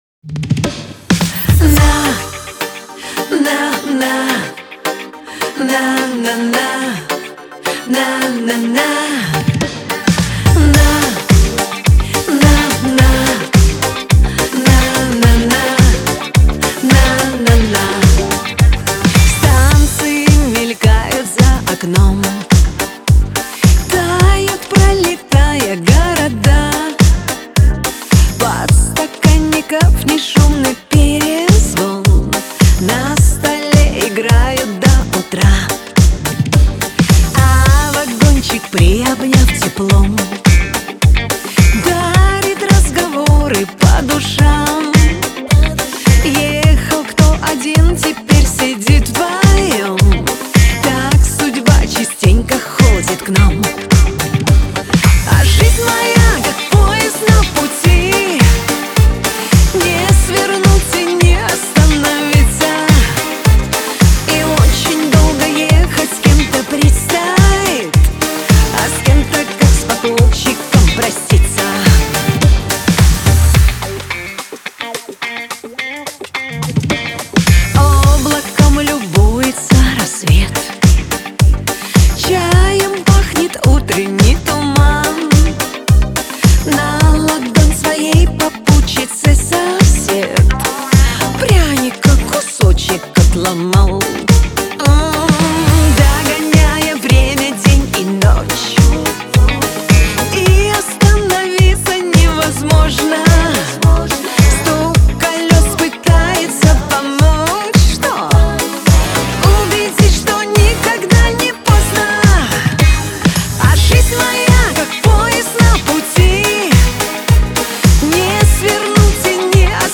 диско
Лирика
Шансон